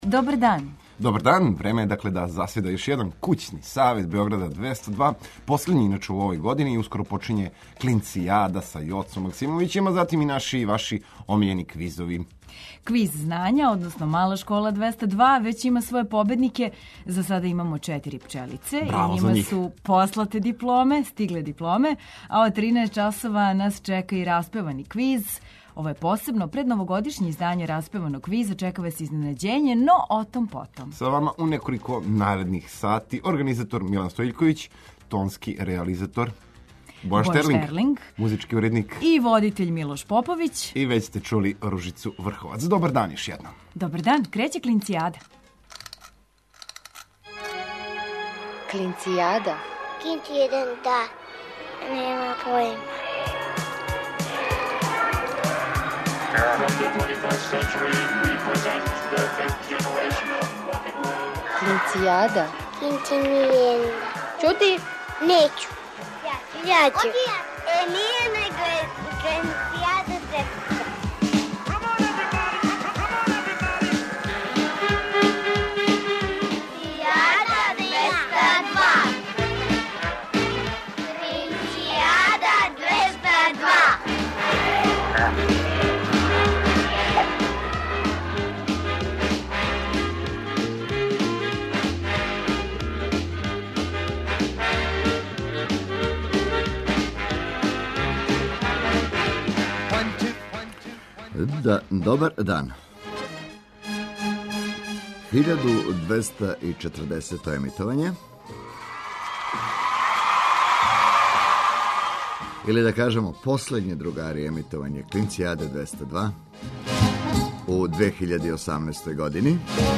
Пред вама је потпуно другачије издање „Распеваног квиза” са гостима.